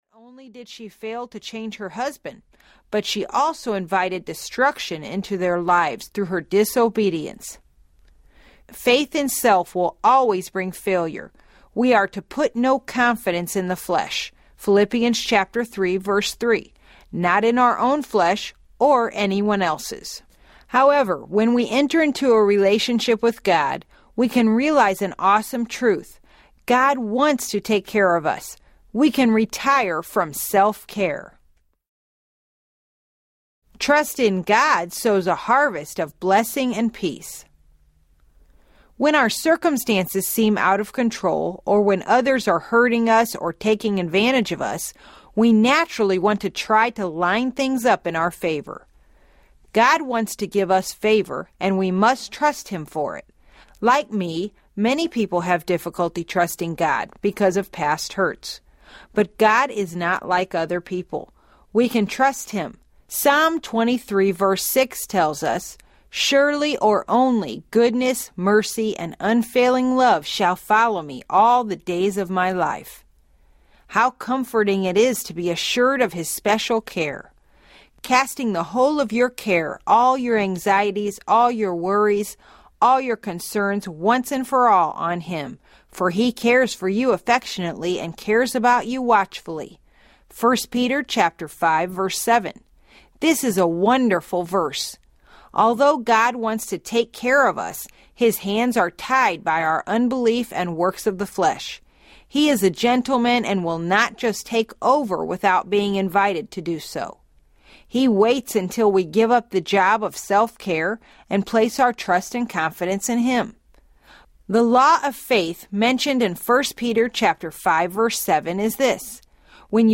Conflict Free Living Audiobook
Narrator
6 Hrs. – Unabridged